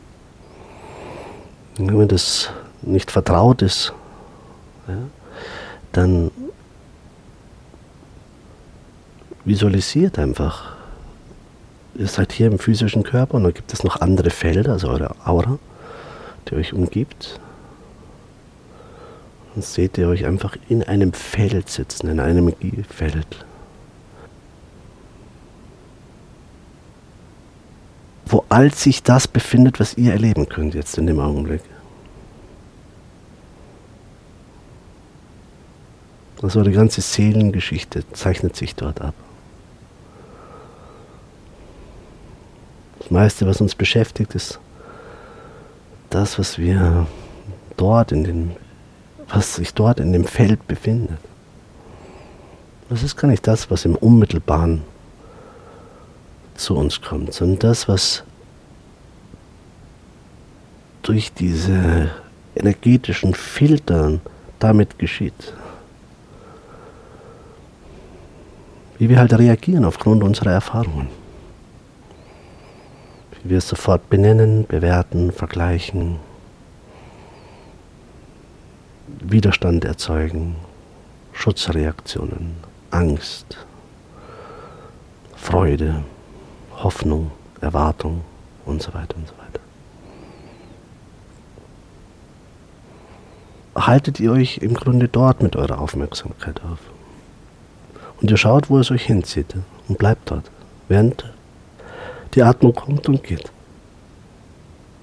Meditation Live-Aufnahmen